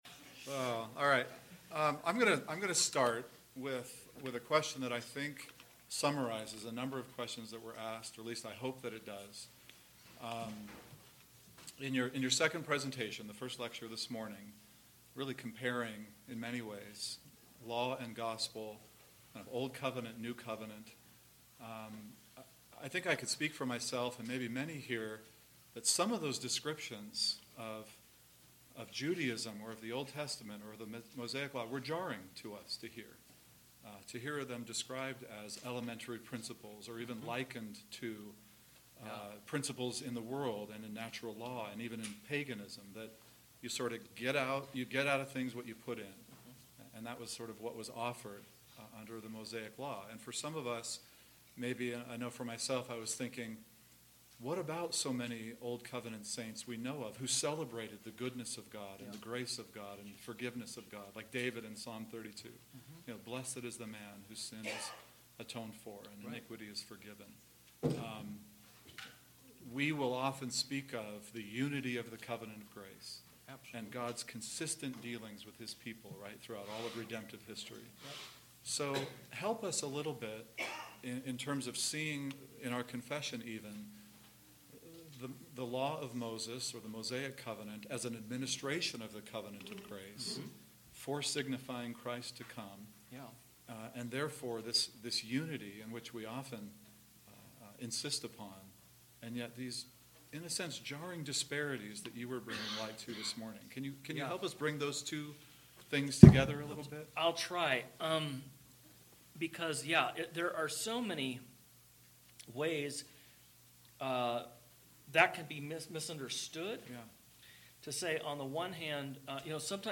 2019 Cheyenne Reformation Conference Q & A
Q&A Session